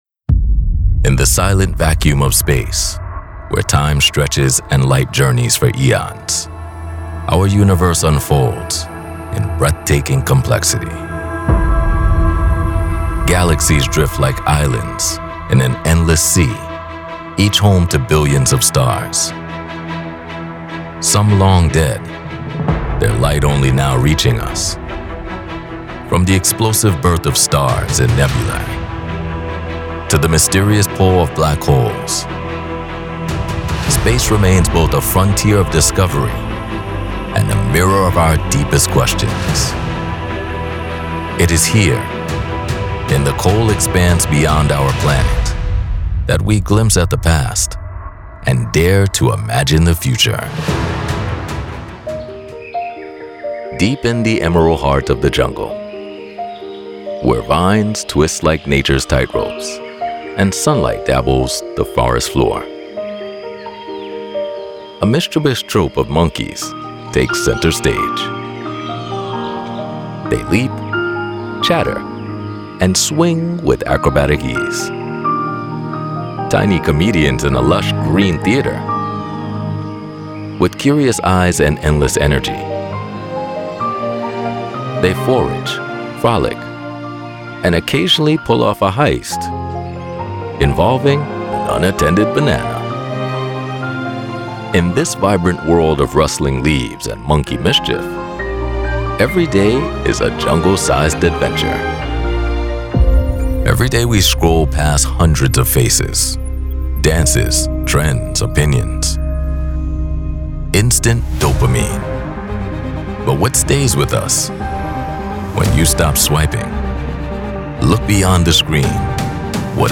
Profound, Resonant, Real.
Documentary